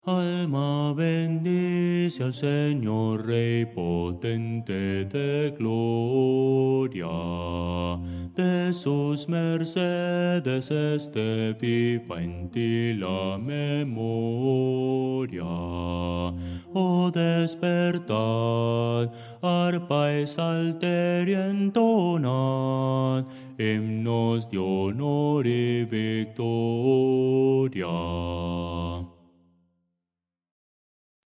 Voces para coro
Bajo – Descargar